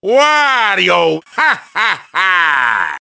One of Wario's voice clips in Mario Kart 7